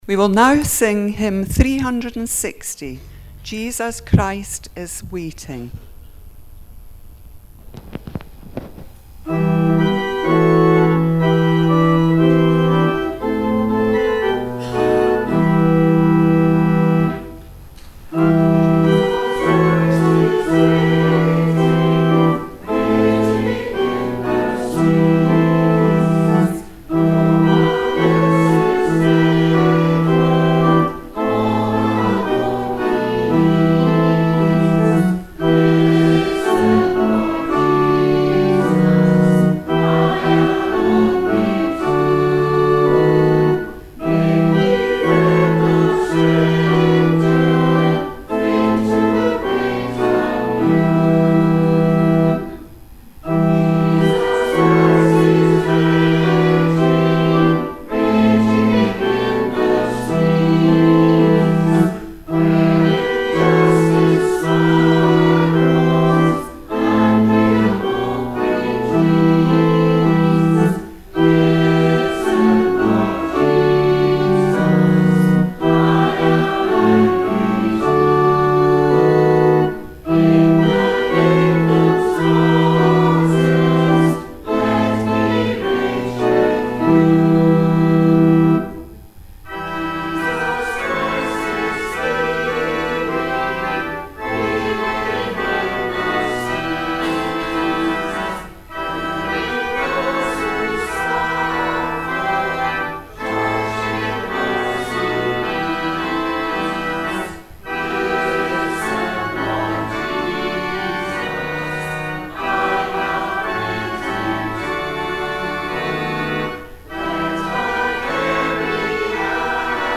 Time to sing